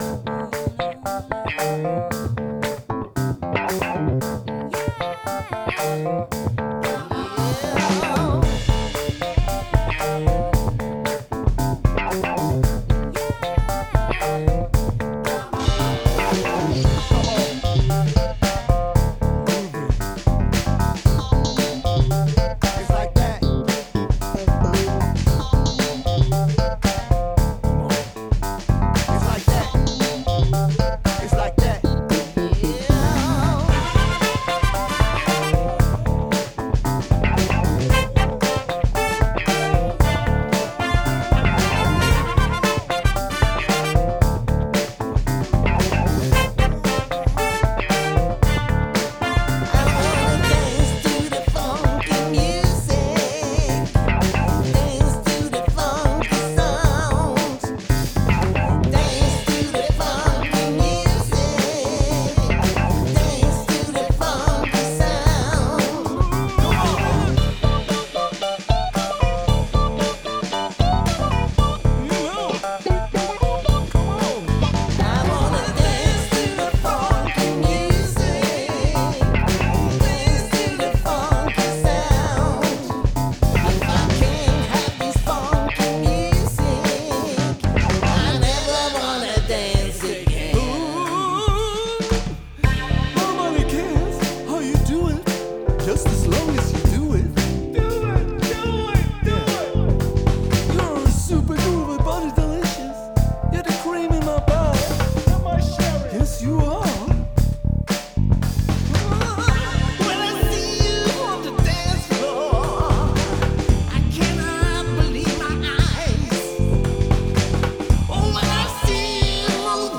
Check out this funky tune